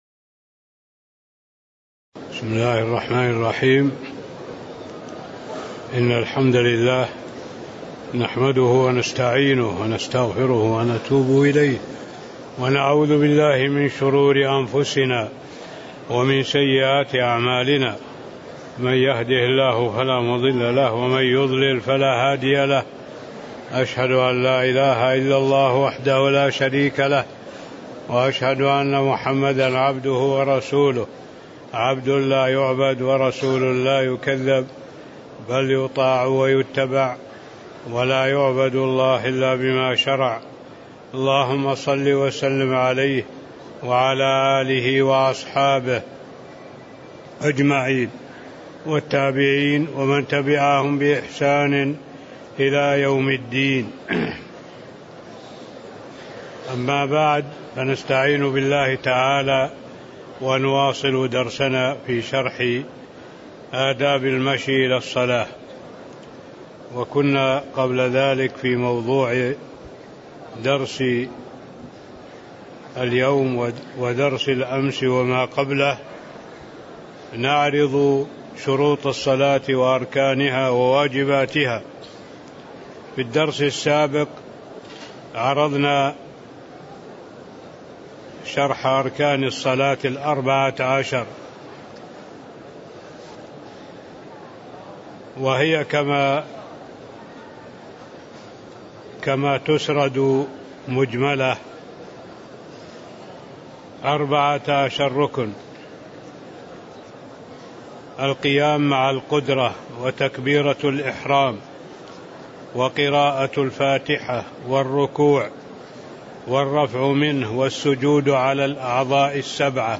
تاريخ النشر ٢٦ ذو الحجة ١٤٣٥ هـ المكان: المسجد النبوي الشيخ